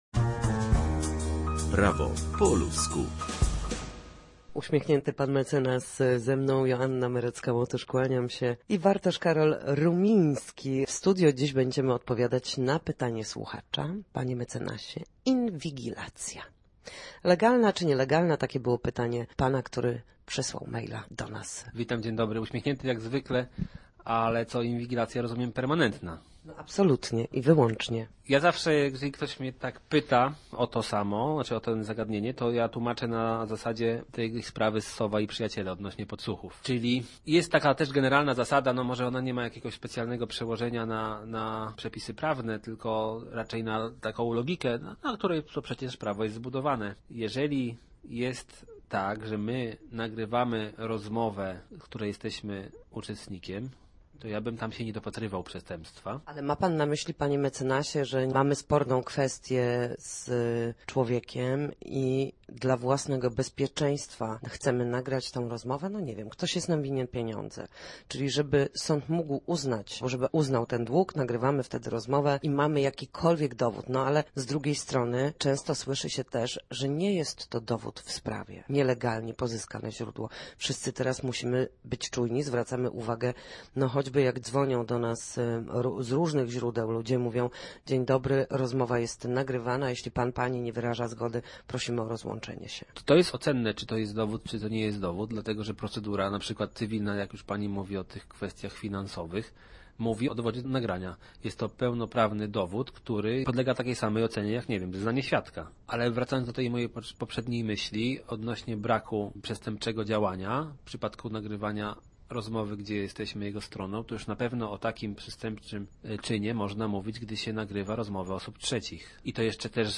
W każdy wtorek o godzinie 13:40 na antenie Studia Słupsk przybliżamy państwu meandry prawa. W naszym cyklu prawnym gościmy ekspertów, którzy odpowiadają na jedno konkretne pytanie związane z zachowaniem w sądzie lub podstawowymi zagadnieniami prawnymi.